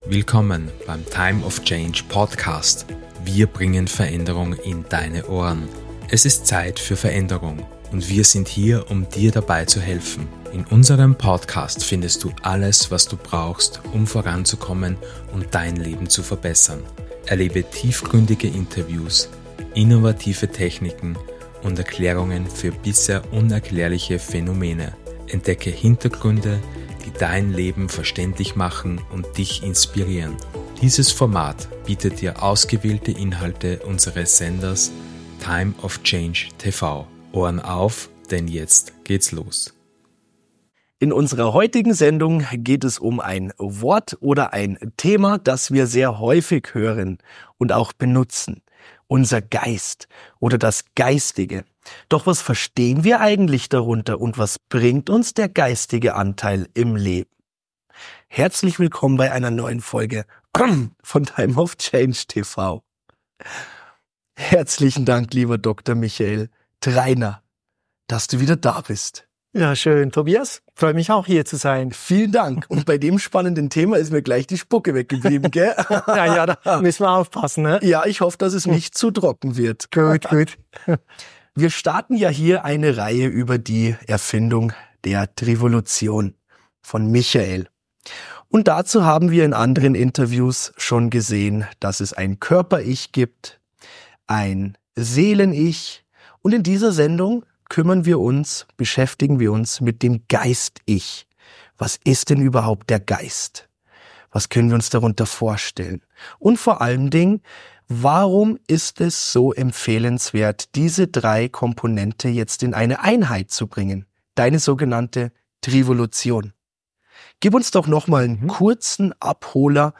Entdecke in diesem Interview, wie Du Deinen Geist ganzheitlich entwickeln und nutzen kannst.